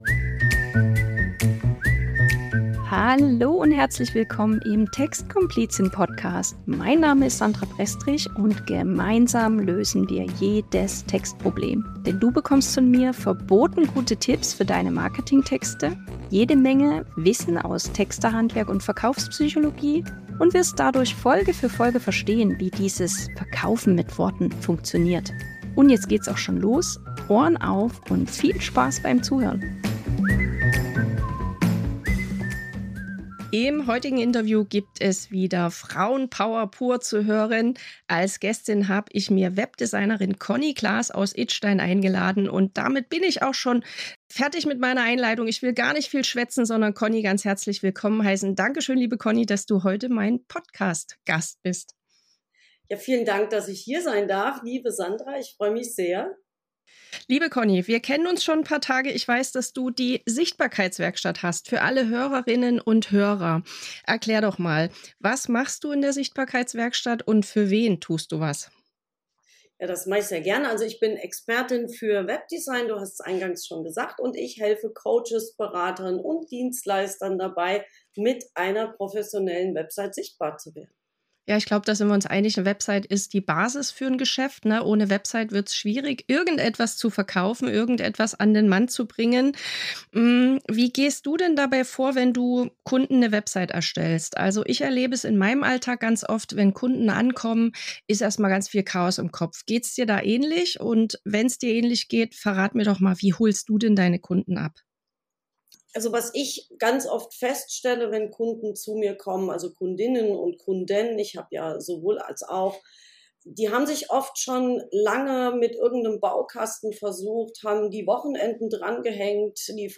#49 – Interview: Die 3 häufigsten Fehler, die "Selberbastler" auf Webseiten machen ~ Die Text-Komplizin - Verkaufsstark Texten fürs Business Podcast